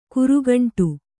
♪ kurugaṇṭu